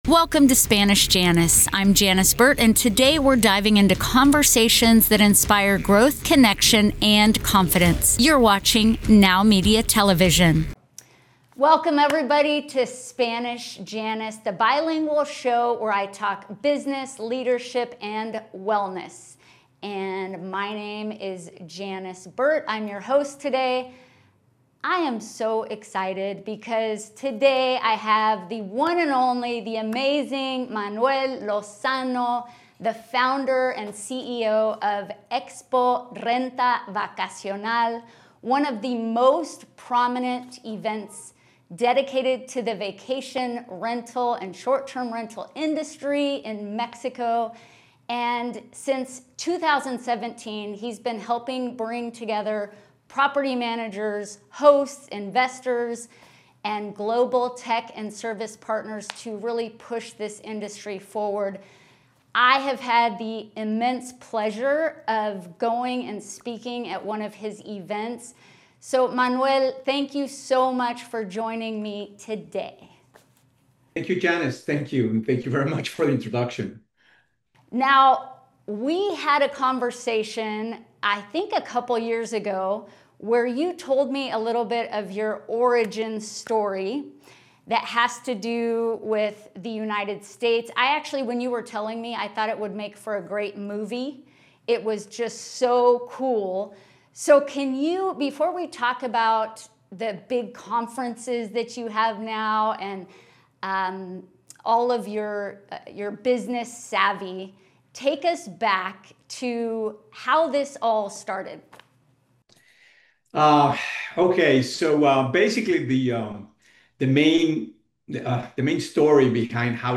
for an inspiring and insightful conversation.